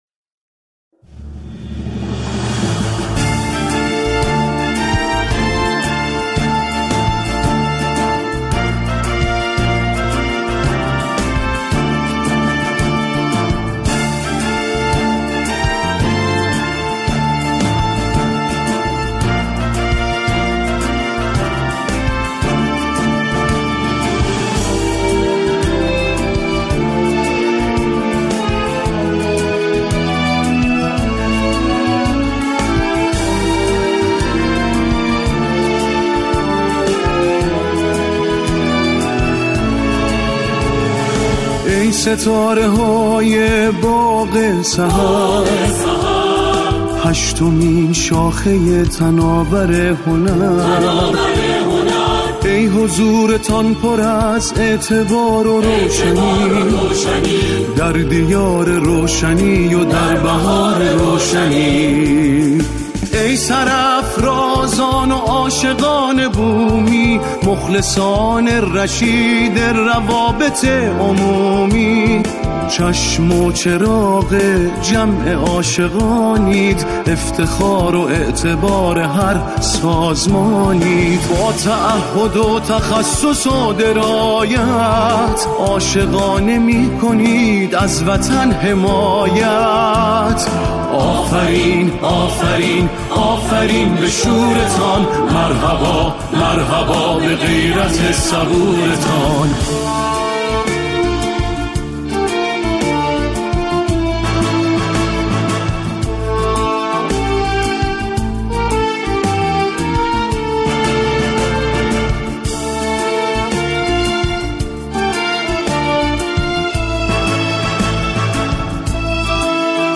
همخوانی شعری